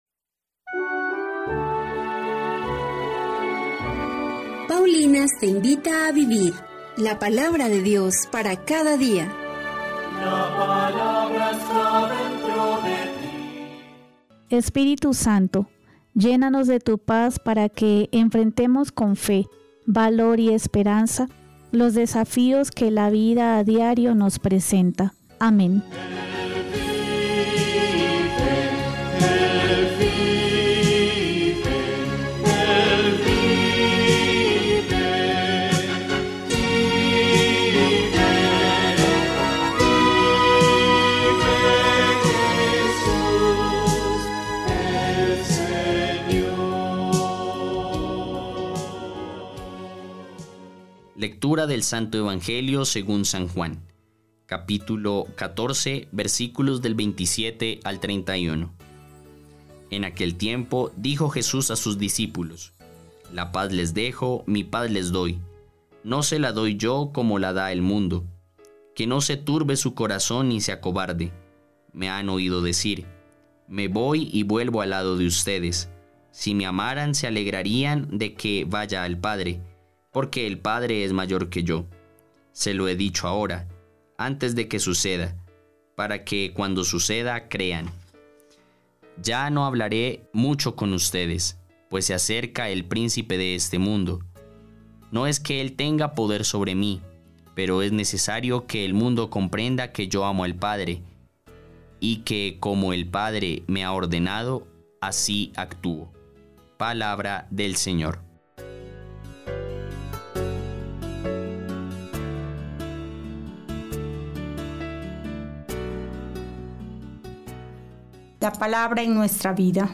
Liturgia diaria
Liturgia-30-de-Abril.mp3